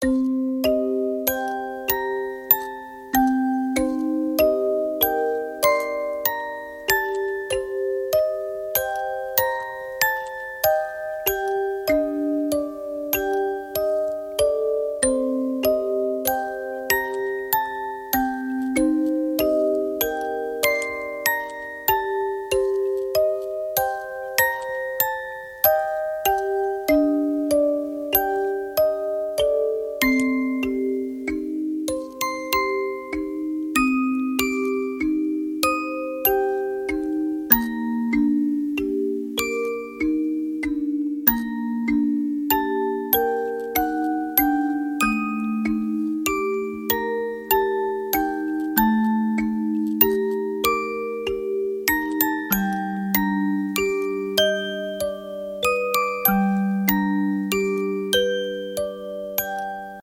音樂盒的音色，有些詭異的旋律，營造出今年話劇予人最主要的印象。